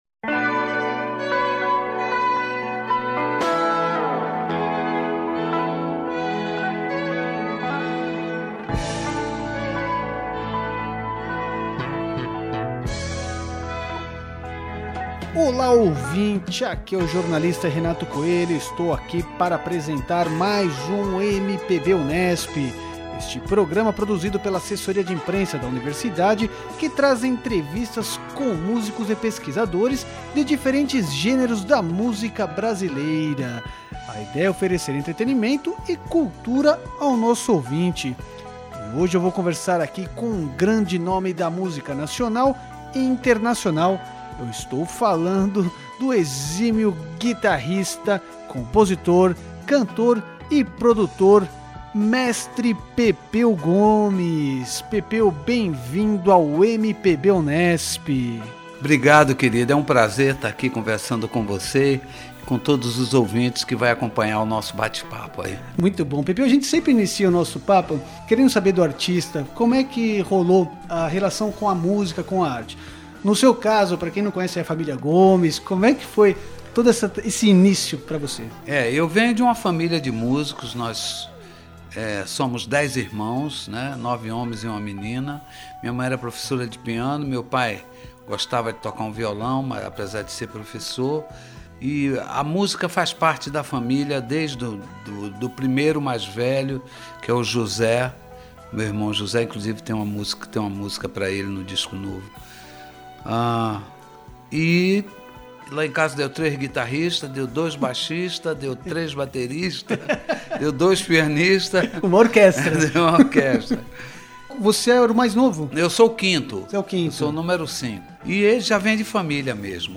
O PodMPB Unesp desta semana é com o grande guitarrista, compositor, cantor e produtor Pepeu Gomes. Nesta entrevista ele fala sobre seus 50 anos de carreira, os Novos Baianos, suas técnicas e fusões entre João Gilberto e Jimi Hendrix, o novo álbum Eterno Retorno (44º disco), do show na Virada Cultural no domingo 19/5, às 15h30 no Sesc Itaquera e também sobre a importância da educação e das universidades públicas para os jovens e para cultura brasileira de forma geral.